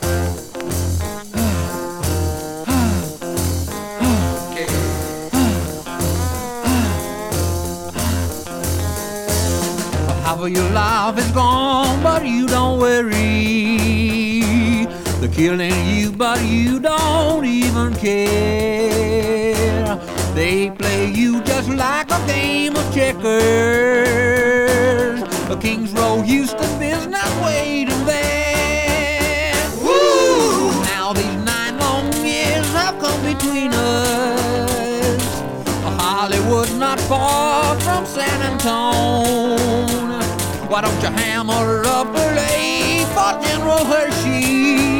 Rock, Psychedelic, Symphonic　USA　12inchレコード　33rpm　Stereo